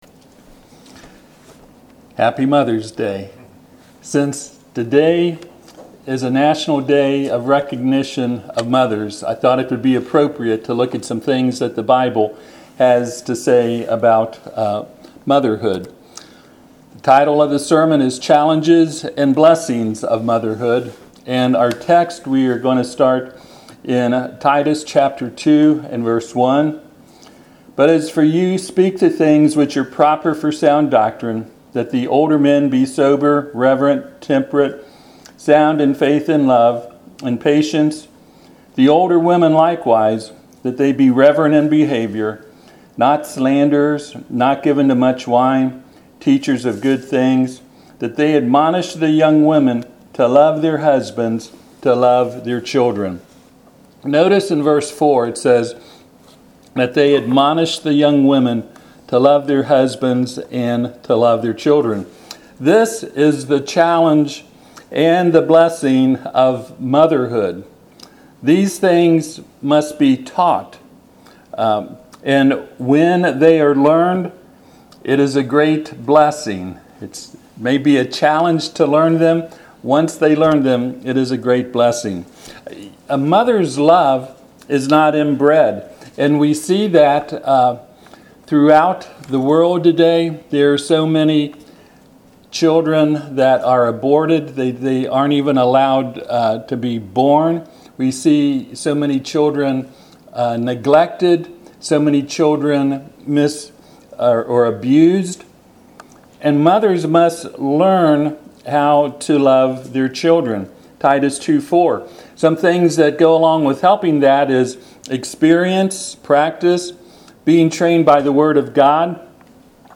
Titus 2:1-4 Service Type: Sunday AM « Revelation